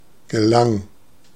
Ääntäminen
Ääntäminen Tuntematon aksentti: IPA: [ɡəˈlaŋ] Haettu sana löytyi näillä lähdekielillä: saksa Käännöksiä ei löytynyt valitulle kohdekielelle. Gelang on sanan gelingen imperfekti.